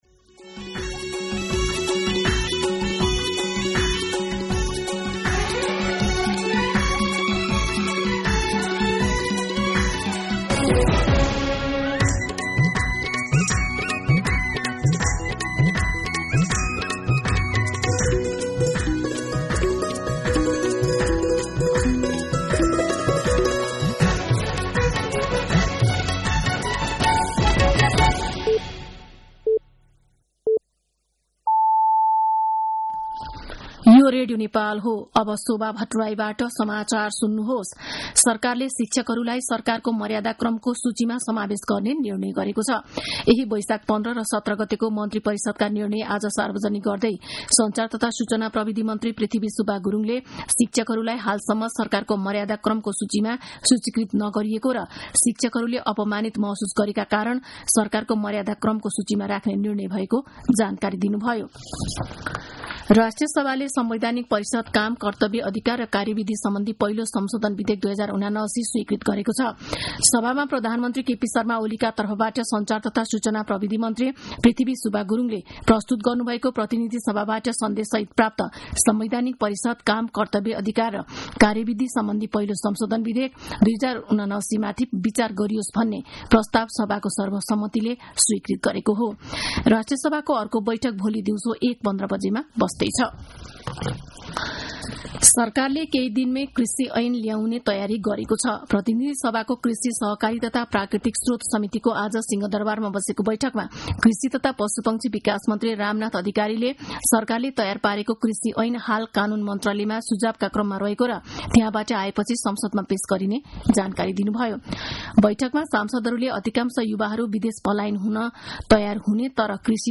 दिउँसो ४ बजेको नेपाली समाचार : २१ वैशाख , २०८२
4-pm-Nepali-News.mp3